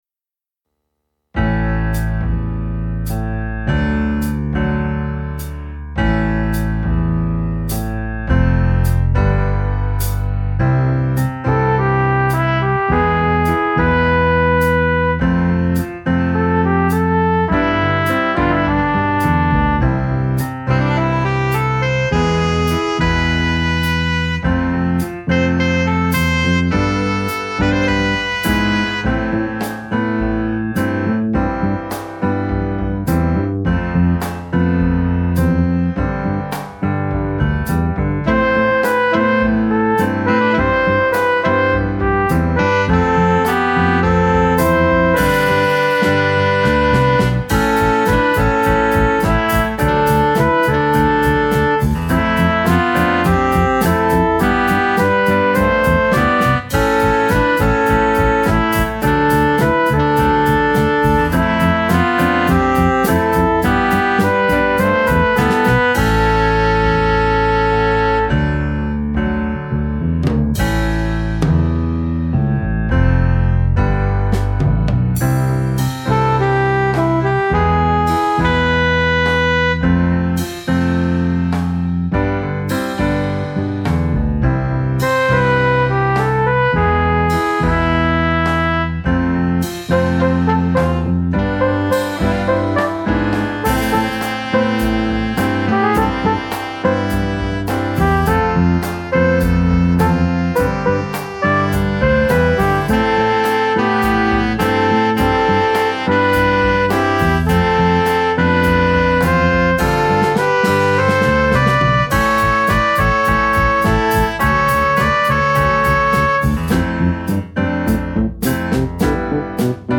minus Instrument 3